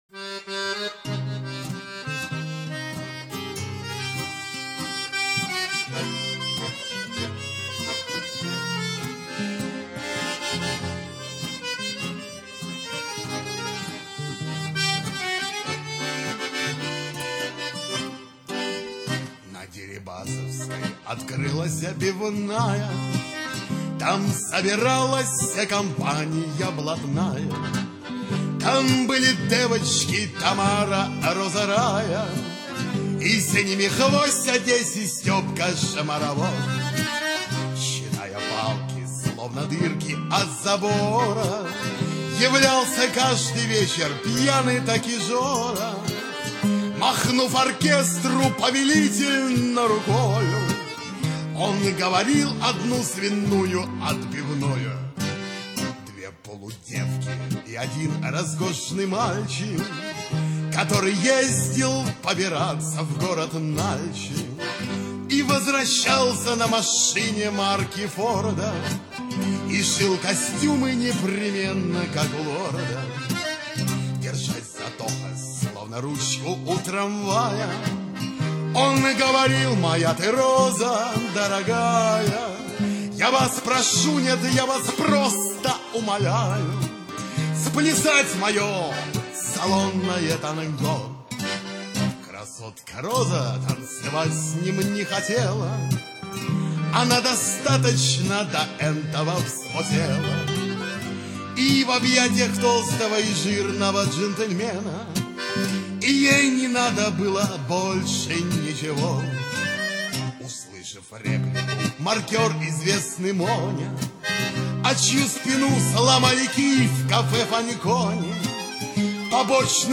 Архив ресторанной музыки